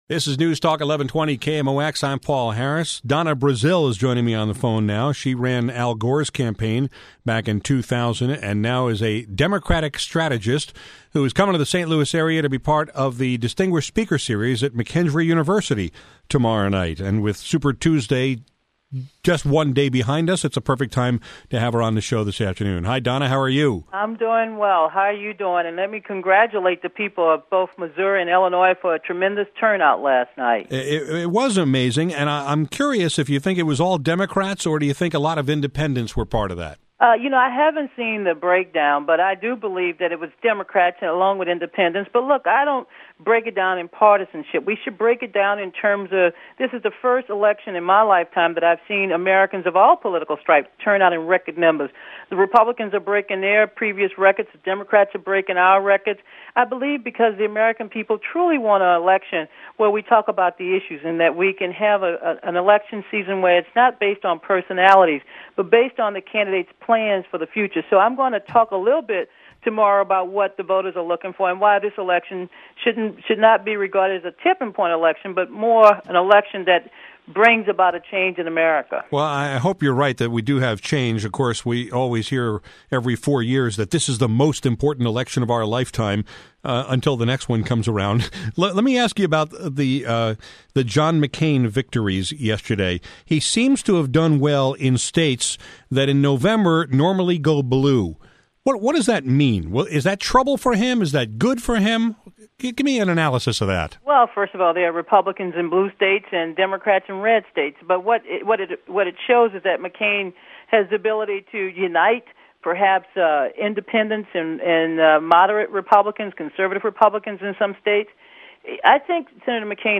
Today, I talked with Democratic strategist Donna Brazile about the Super Tuesday results, how much Hillary Clinton’s lack of money will affect her campaign, and whether John McCain’s success in the blue states will translate to votes in the fall.